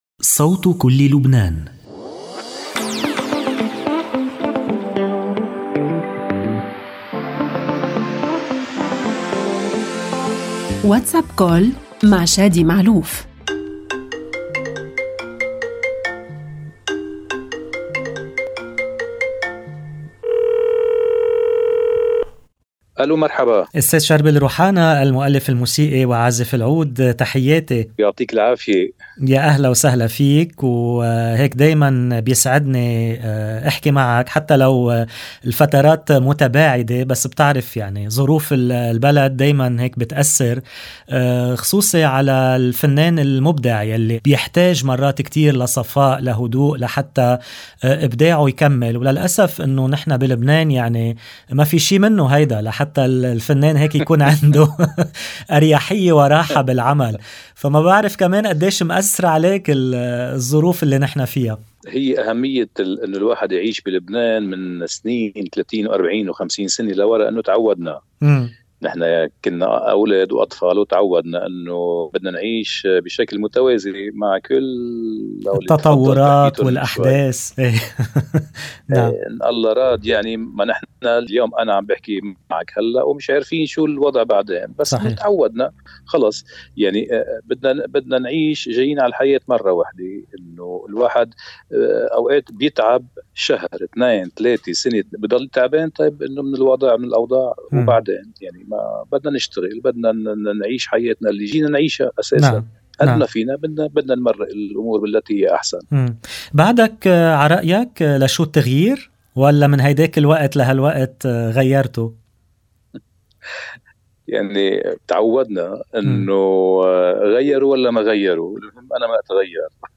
WhatsApp Call المؤلف الموسيقي وعازف العود شربل روحانا Mar 09 2024 | 00:15:53 1x 00:00 / 00:15:53 Subscribe Share RSS Feed Share Link Embed